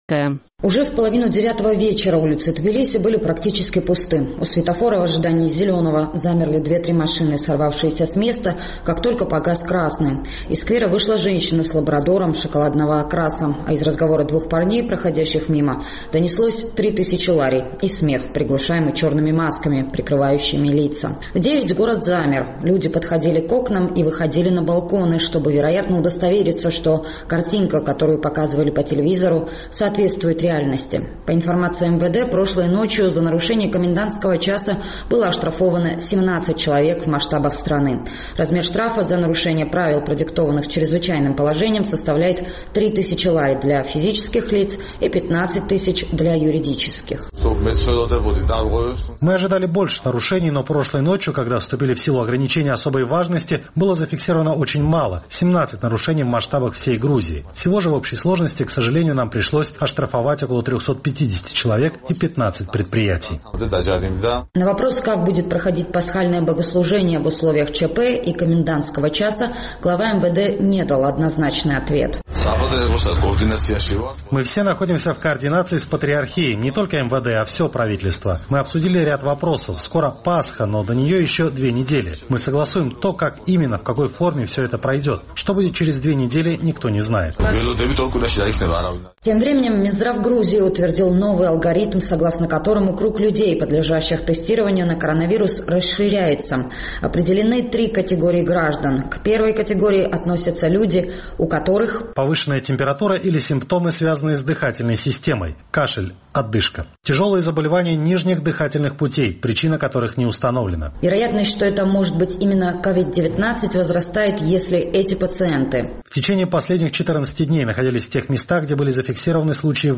Репортаж из покинутого города